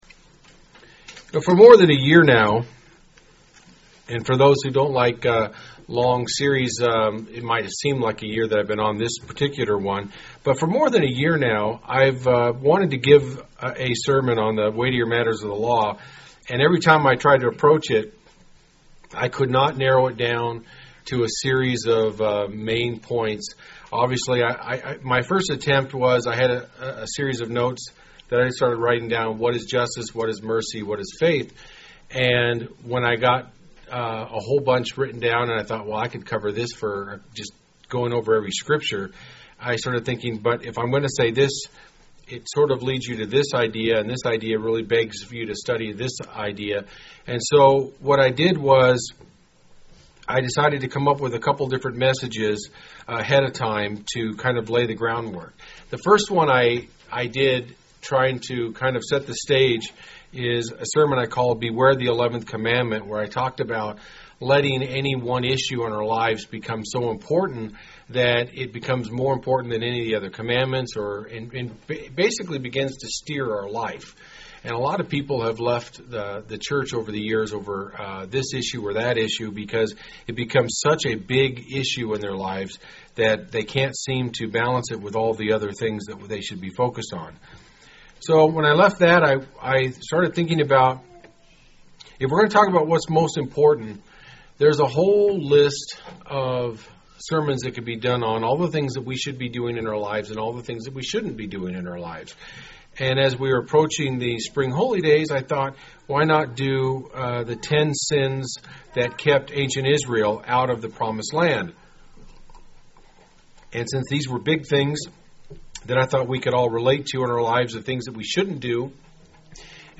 UCG Sermon Notes For more than a year now I have had this thought to give a sermon on the weightier matters of the law.